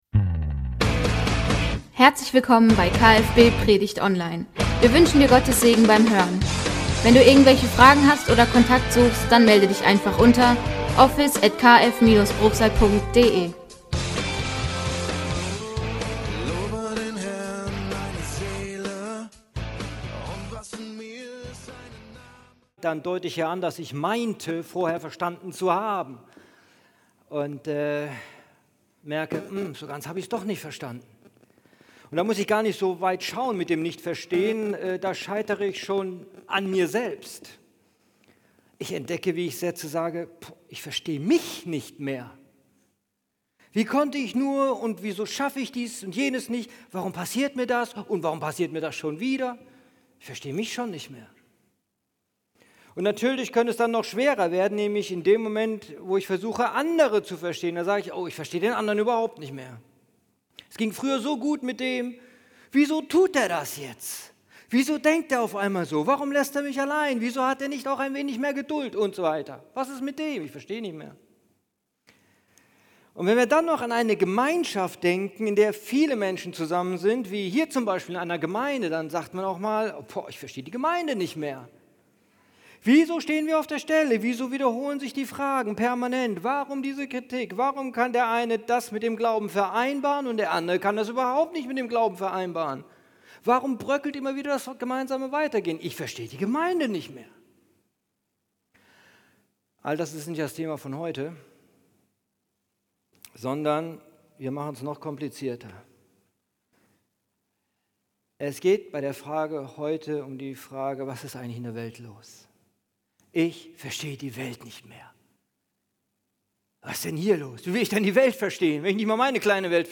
Gottesdienst: Ich versteh’ die Welt nicht mehr…